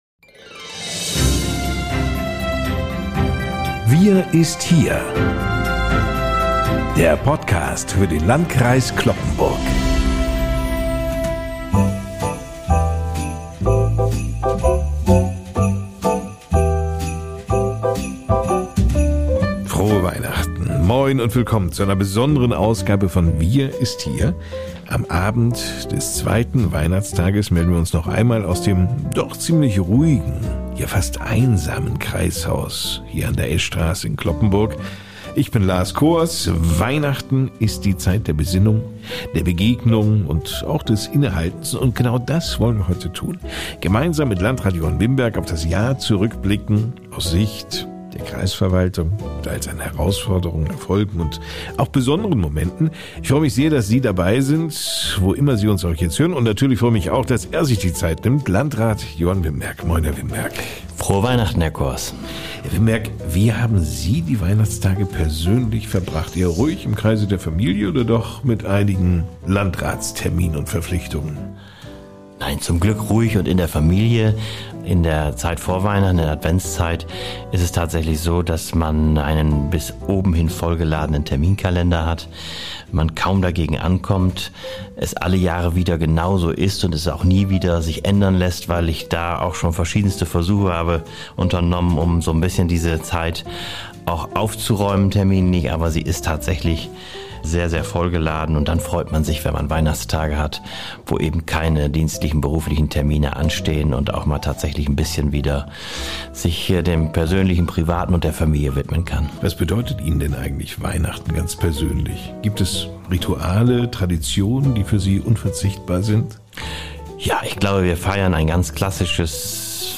Folge 59: Rückblick, Klartext, Ausblick – Landrat Johann Wimberg im Weihnachtsgespräch ~ WIR IST HIER! Podcast